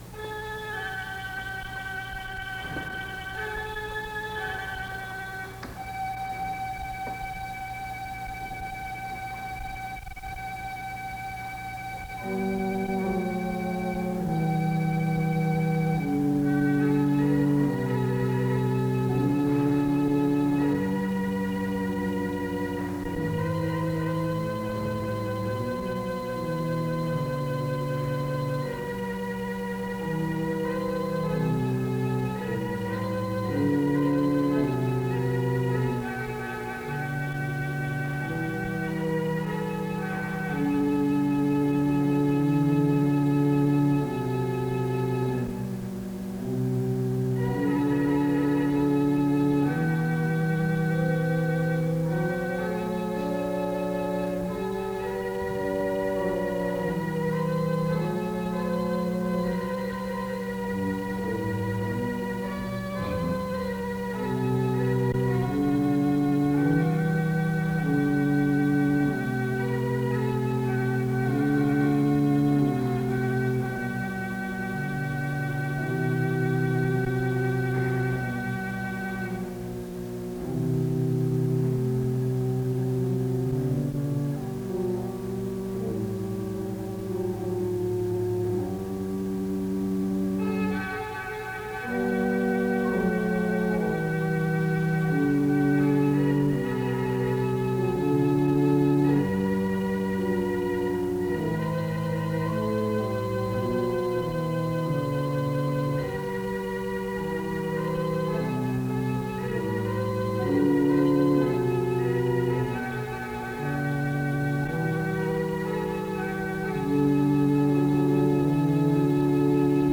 The service begins with music from 0:00-2:58.
Music plays from 4:30-5:04. Another prayer is given from 5:30-7:30.